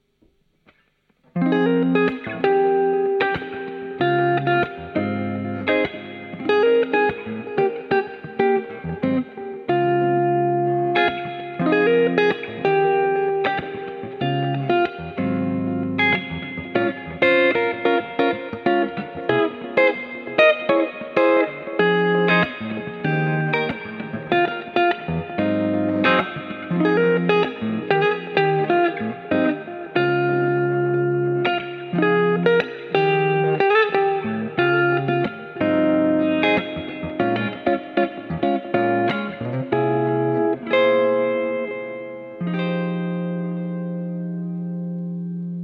cleansound rumgefummel